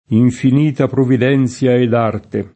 provvidenza [provvid$nZa] s. f. — anche con P- maiusc. per indicare la provvidenza divina; sim. il pers. f. e cogn.